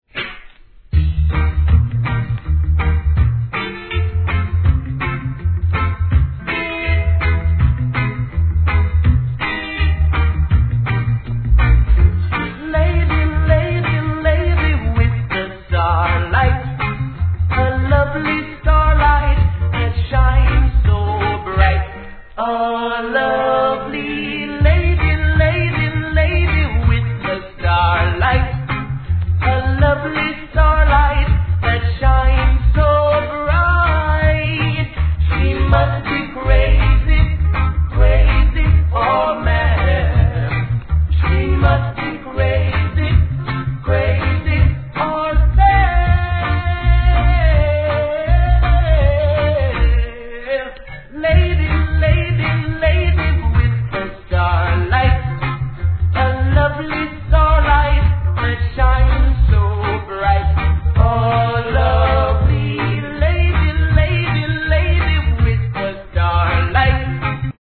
REGGAE
ROCKSTEADY